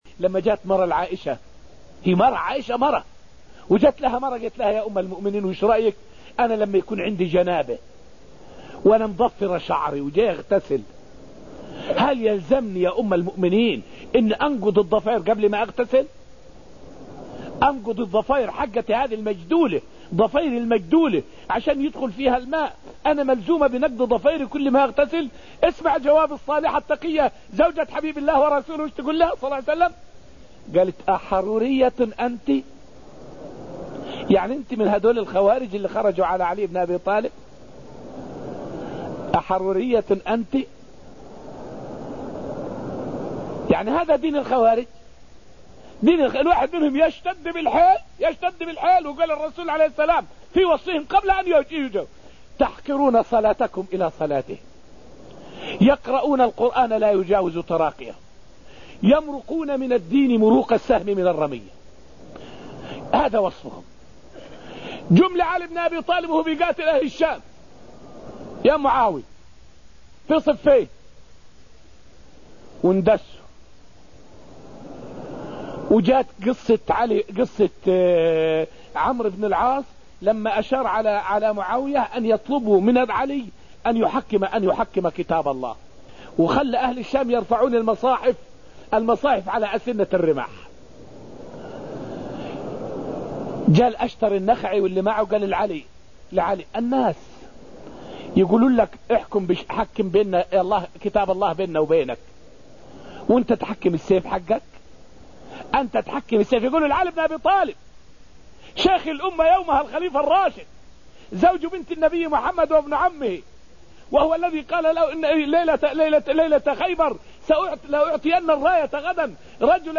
فائدة من الدرس الثالث والعشرون من دروس تفسير سورة الحديد والتي ألقيت في المسجد النبوي الشريف التشدد ليس من خصال المسلم.